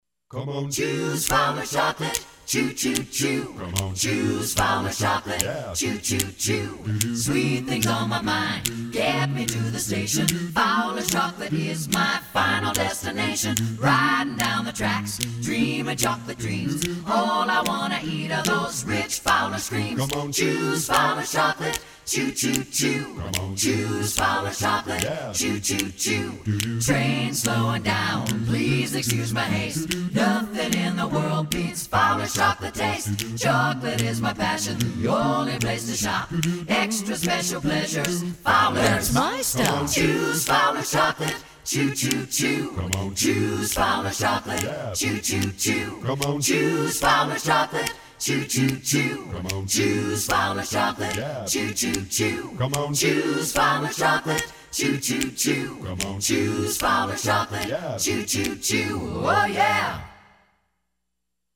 worked with local musicians